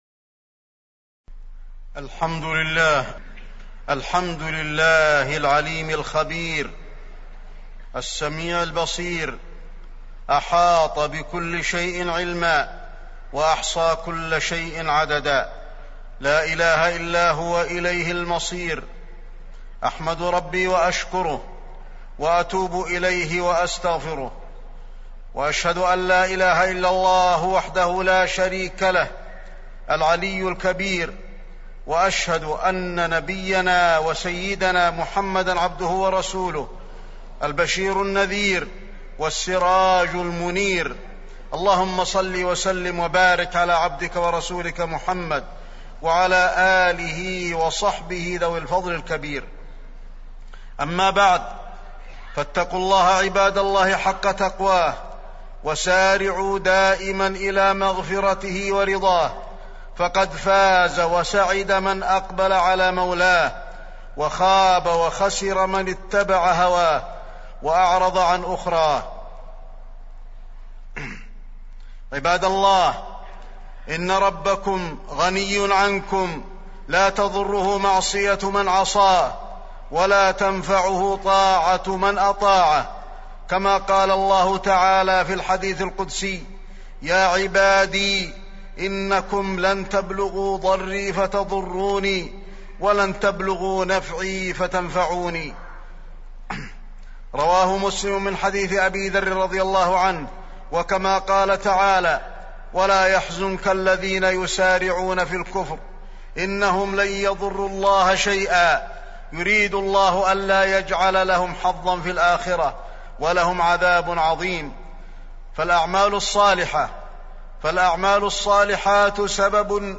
تاريخ النشر ٥ ربيع الثاني ١٤٢٦ هـ المكان: المسجد النبوي الشيخ: فضيلة الشيخ د. علي بن عبدالرحمن الحذيفي فضيلة الشيخ د. علي بن عبدالرحمن الحذيفي حسن الخاتمة